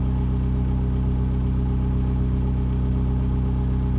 v8_01_idle.wav